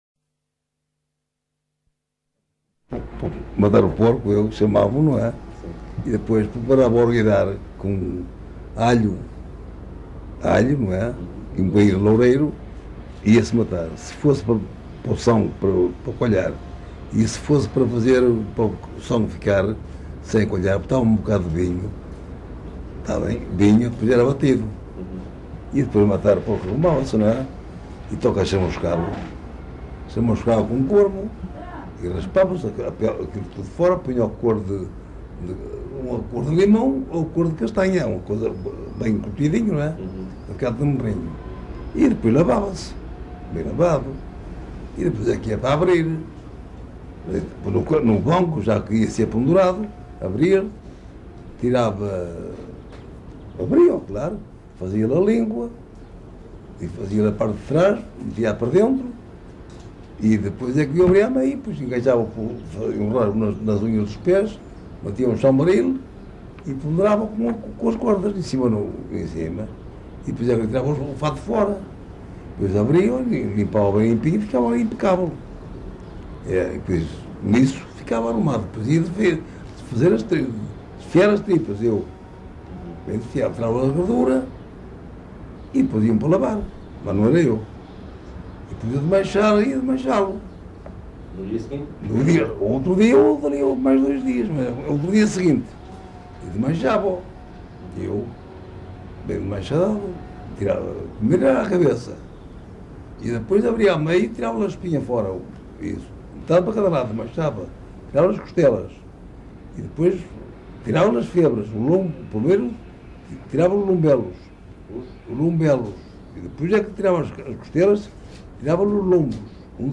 LocalidadeArcos de Valdevez (Arcos de Valdevez, Viana do Castelo)